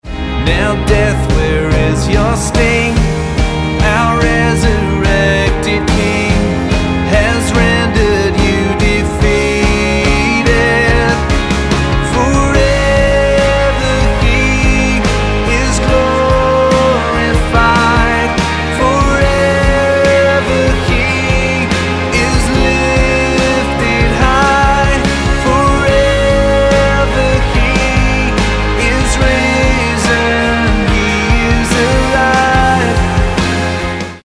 Ab